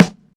GUY_SNR.wav